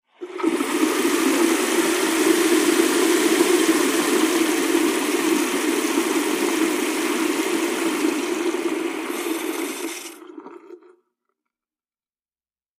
fo_sink_runwater_med_01_hpx
Bathroom sink faucet runs at slow, medium and fast flows.